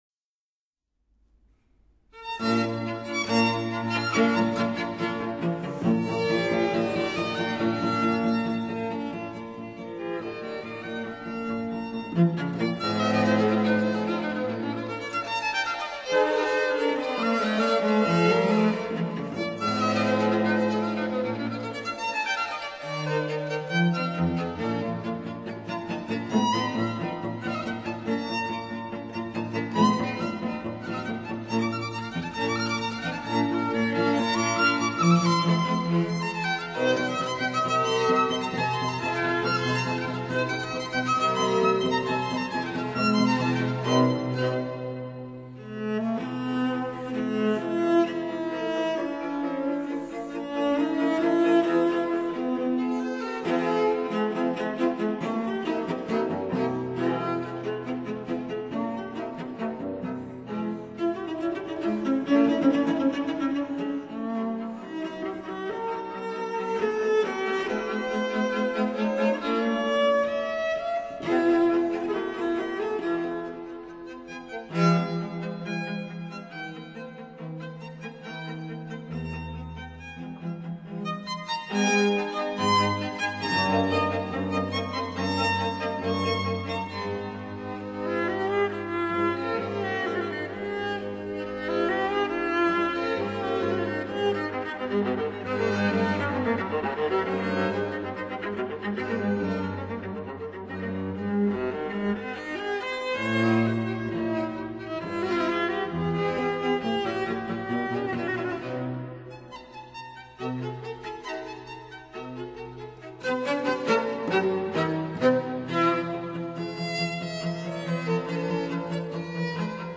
Quartet in B flat major: Allegro spiritoso    [0:09:41.21]